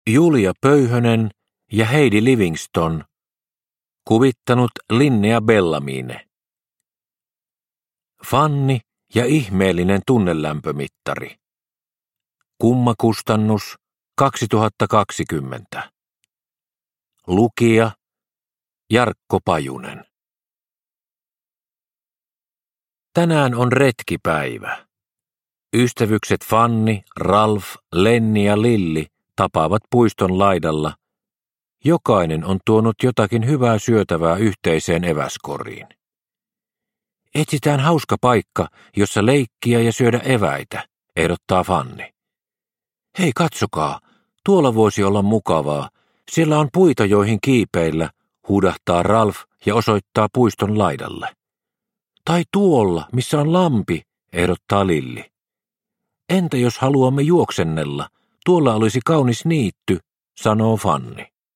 Fanni ja ihmeellinen tunnelämpömittari – Ljudbok – Laddas ner